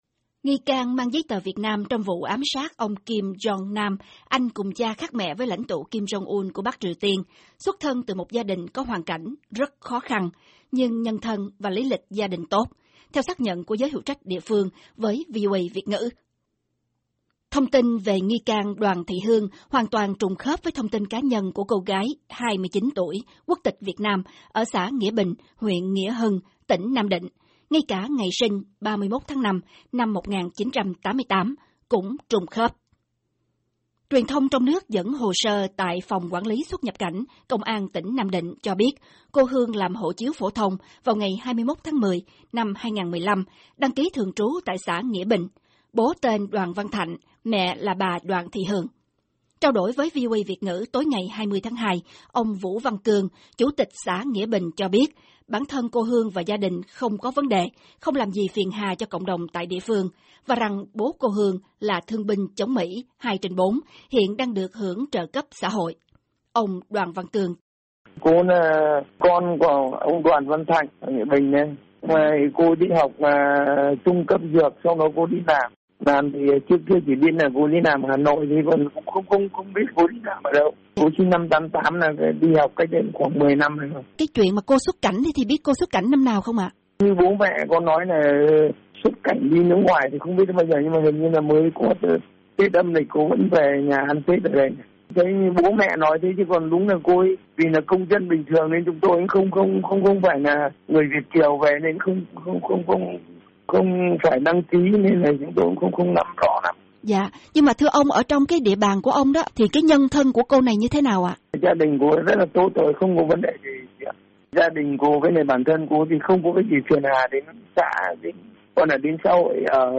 Phỏng vấn Chủ tịch UBND xã Nghĩa Bình